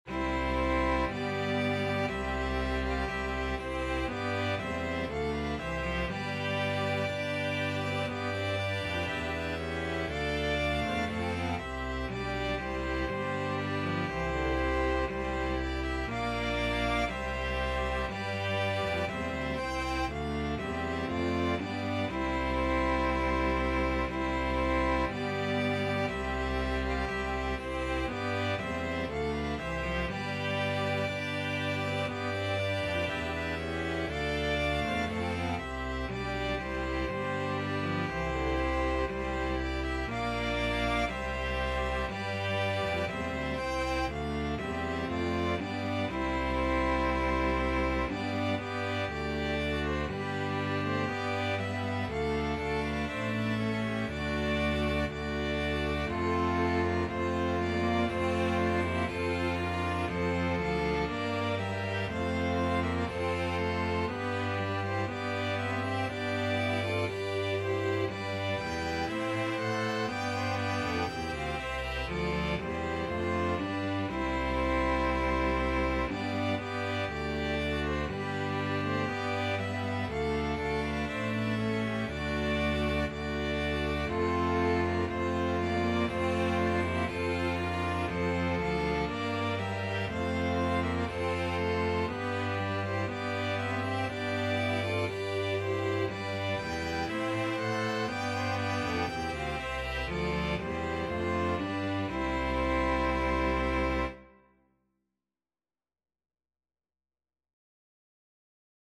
Free Sheet music for String Ensemble
Violin 1Violin 2ViolaCelloDouble Bass
2/2 (View more 2/2 Music)
C major (Sounding Pitch) (View more C major Music for String Ensemble )
Classical (View more Classical String Ensemble Music)